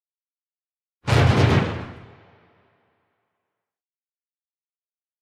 Drum Hits - Symphonic Drums - Final Hits